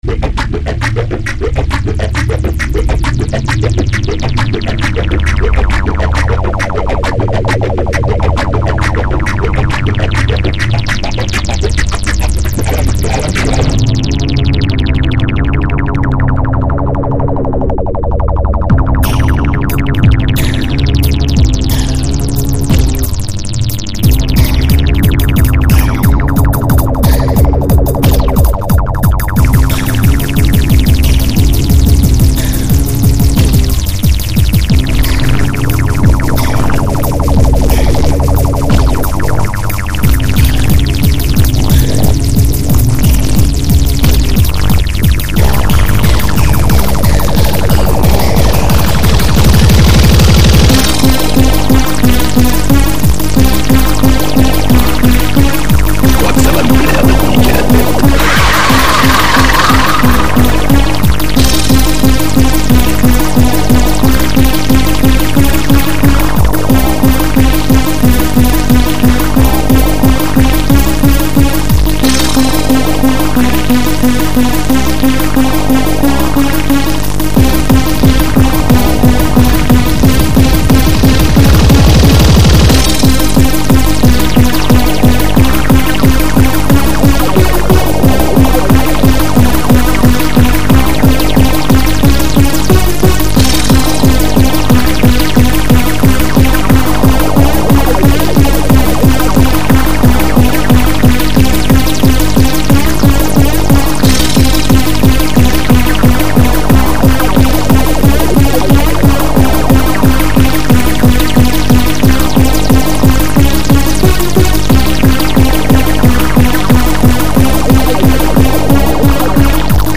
Utilisation de loops de folie en tout sens.